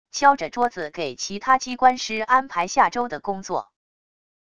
敲着桌子给其他机关师安排下周的工作wav音频